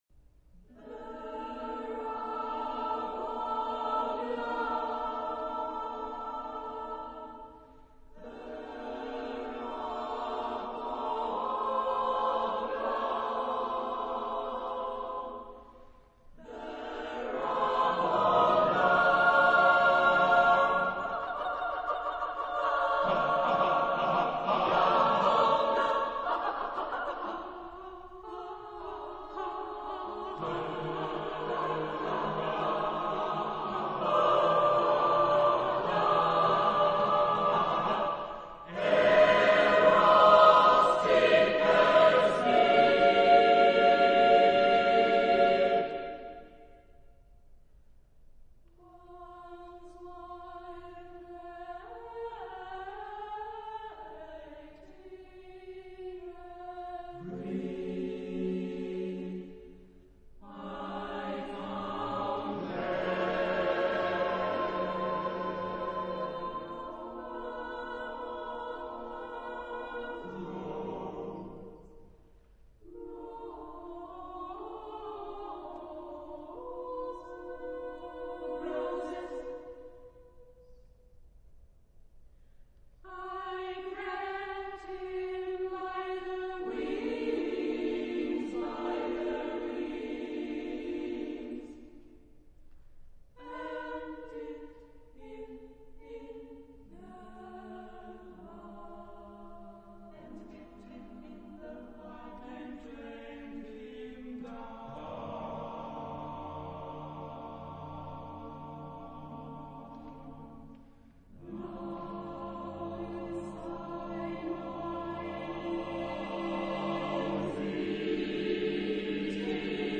Genre-Style-Form: Vocal piece ; Secular
Type of Choir: SSSAAATTTBBB  (12 mixed voices )
Tonality: free tonality
sung by Kammerchor Consono
Discographic ref. : 7. Deutscher Chorwettbewerb 2006 Kiel